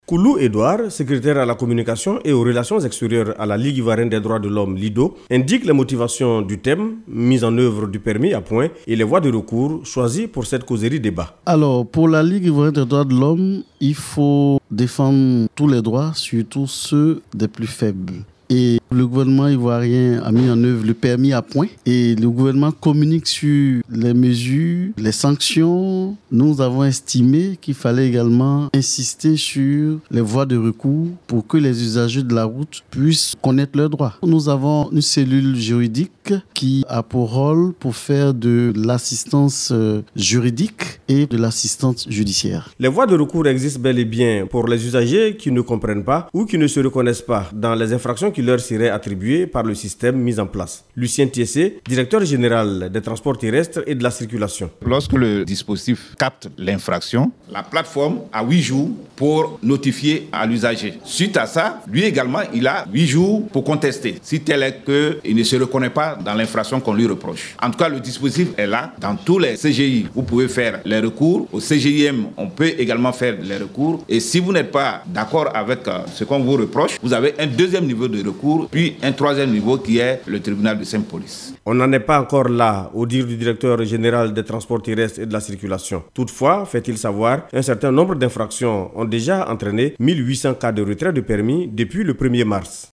Permis à points, la Ligue Ivoirienne des Droits de l’Homme (LIDHO) vient d’organiser une conférence débat autour du thème “mise en œuvre du permis à points et les voies de recours.”
causerie-debat-de-la-lidho-sur-les-voies-de-recours-pour-le-permis-a-points.mp3